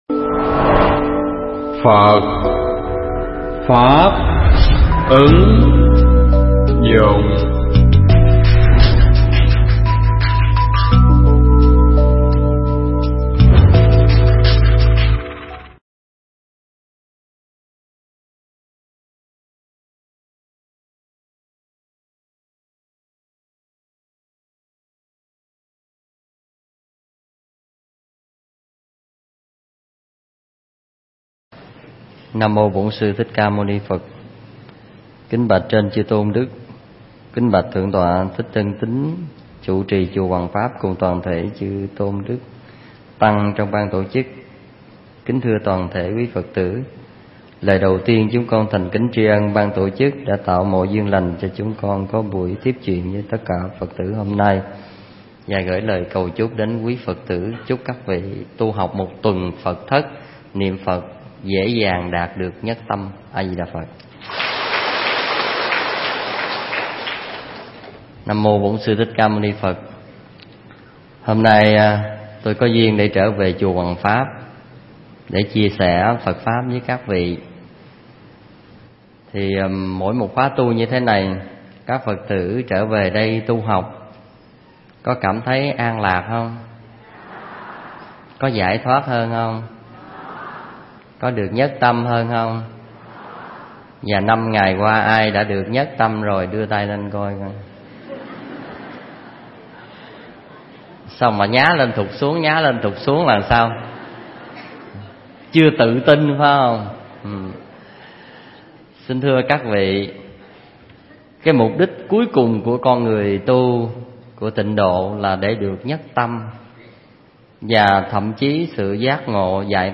Pháp thoại